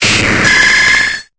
Cri d'Octillery dans Pokémon Épée et Bouclier.